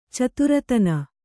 ♪ caturatana